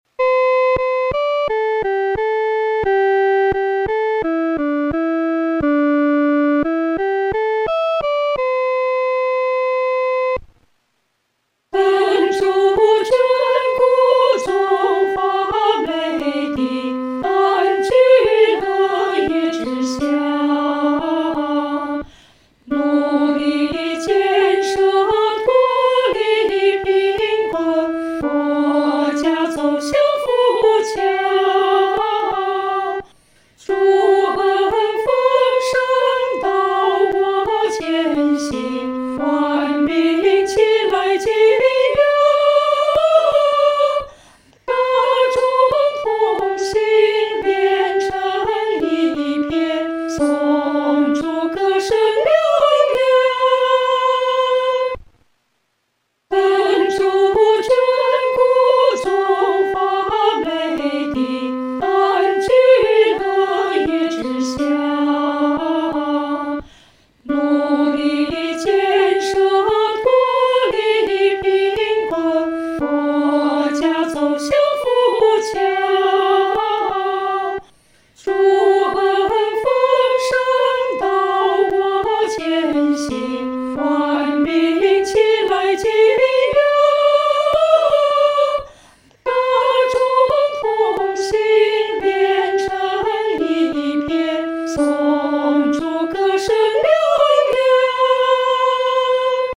合唱
女高